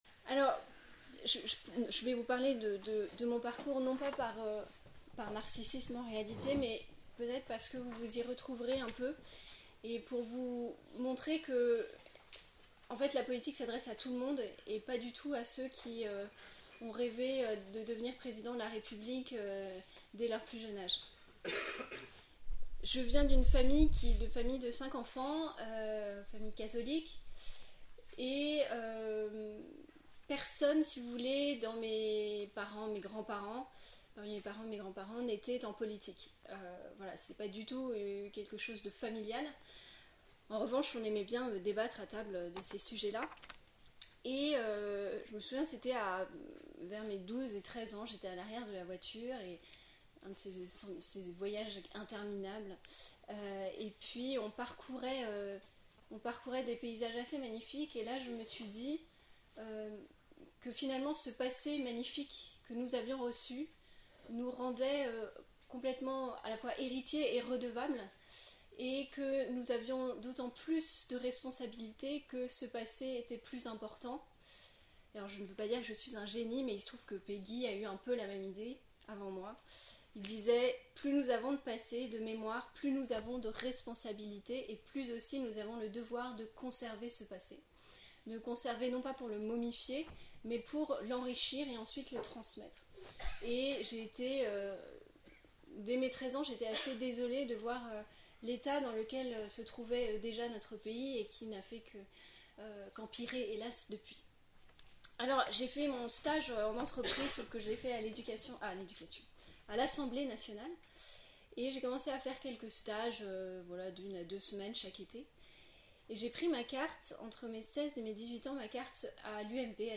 Réécoutez les plénières du Forum Jeunes Pros 2016 :